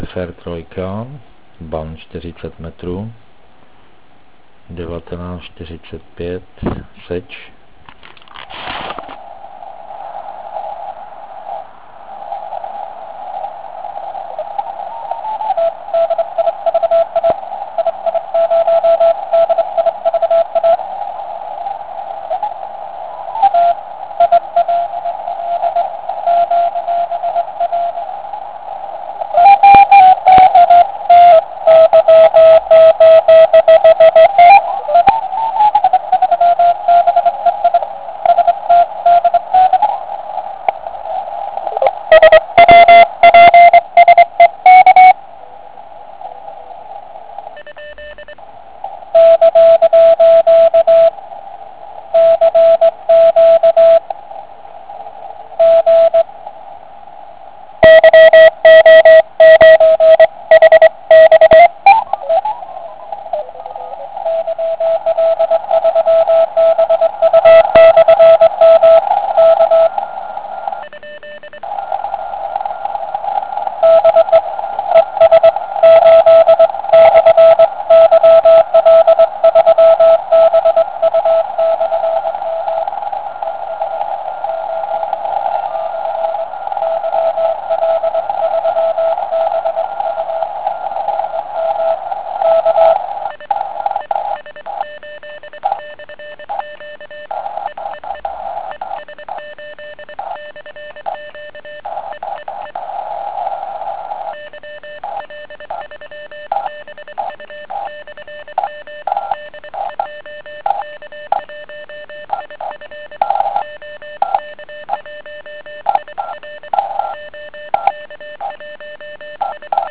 40m SCAN (*.wav 500KB)
Na můj vkus však rádio nepříjemně šumí.
Bohužel typický "Sound" nf filtru s OZ, je zde jasně patrný.